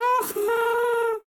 Minecraft Version Minecraft Version 25w18a Latest Release | Latest Snapshot 25w18a / assets / minecraft / sounds / mob / happy_ghast / ambient12.ogg Compare With Compare With Latest Release | Latest Snapshot